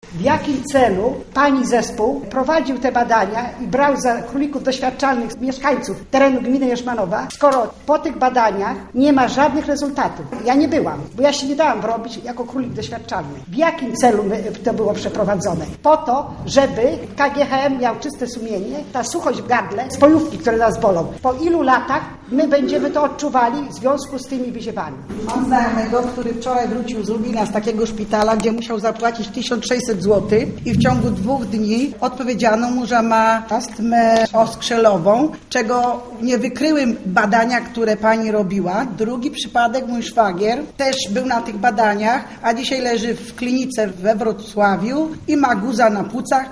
Trudno było więc mówić o znalezieniu szybkiego rozwiązania problemu, tym bardziej, że wiele wypowiedzi było niezwykle emocjonalnych.